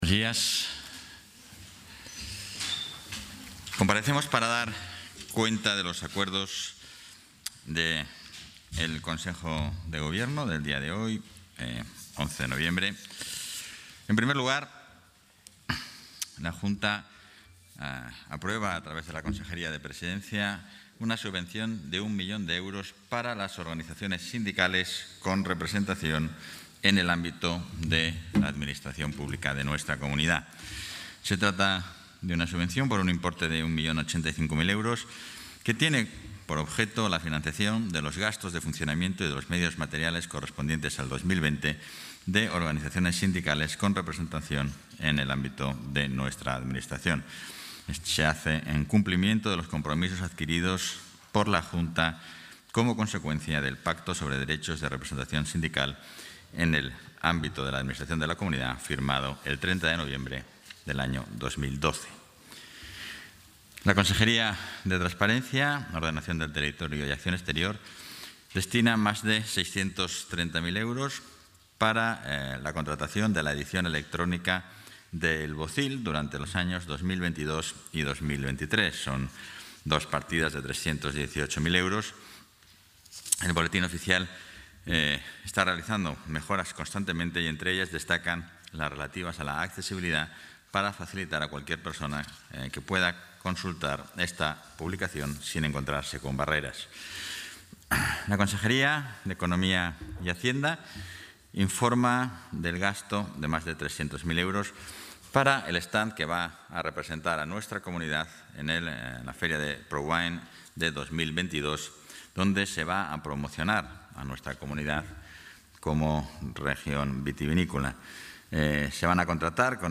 Intervención portavoz.
Consejo de Gobierno del 11 de noviembre de 2021.